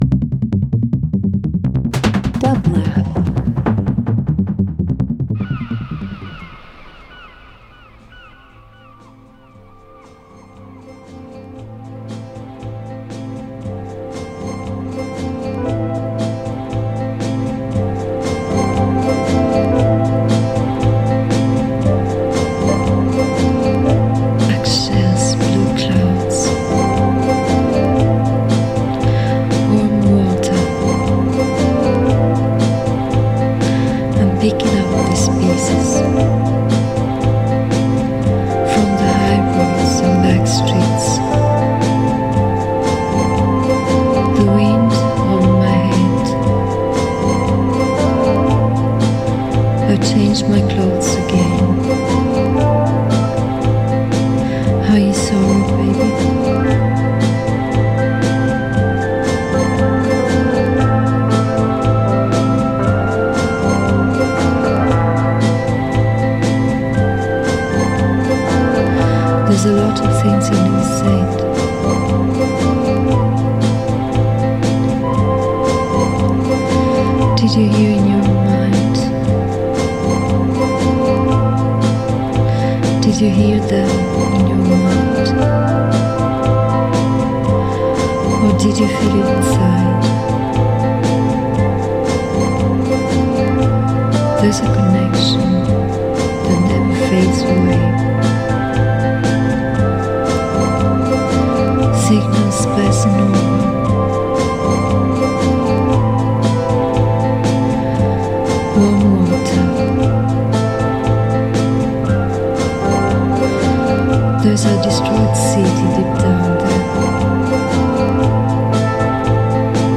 Dub Instrumental Leftfield R&B Voice